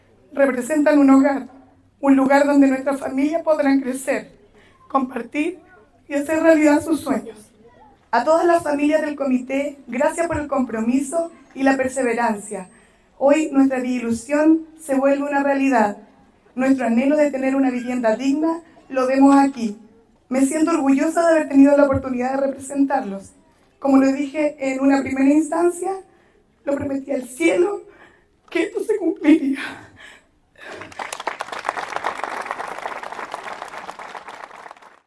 En una emotiva ceremonia, 144 familias de Villa Ilusión, en Cauquenes, recibieron las llaves de sus nuevos hogares.